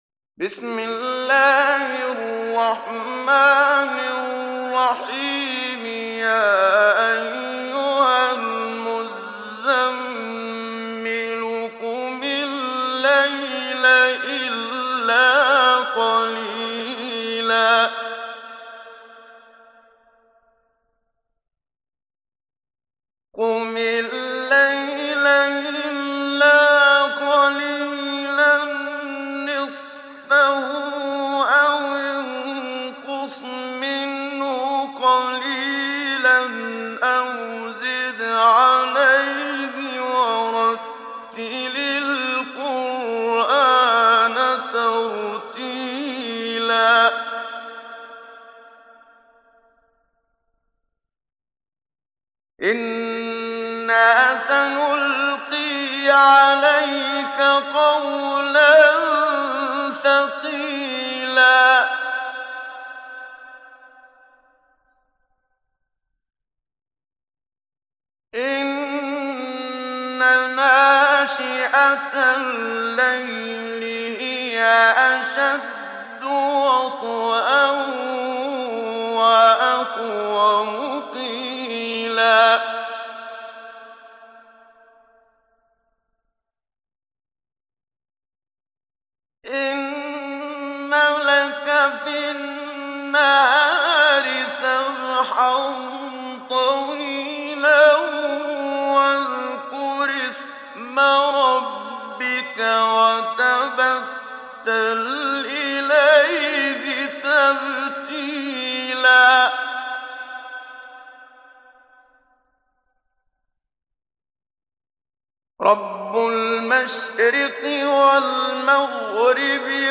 Download Surat Al Muzzammil Muhammad Siddiq Minshawi Mujawwad